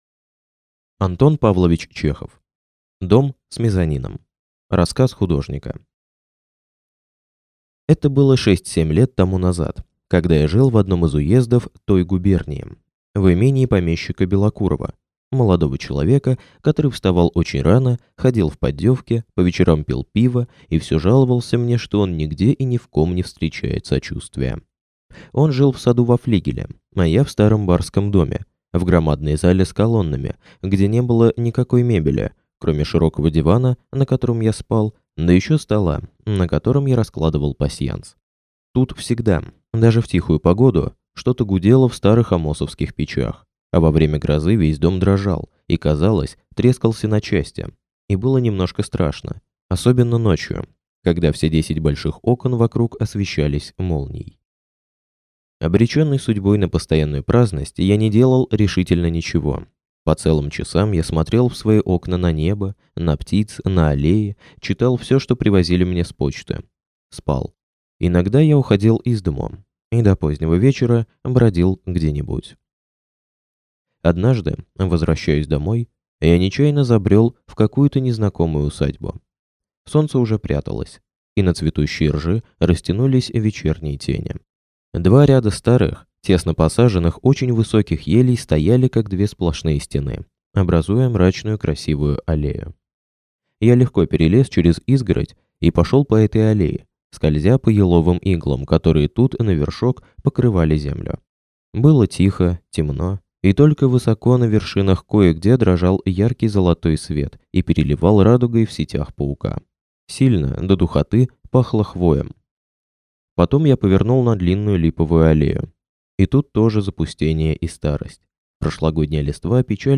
Аудиокнига Дом с мезонином | Библиотека аудиокниг